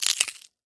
Звук раздавливания чеснока боковой частью ножа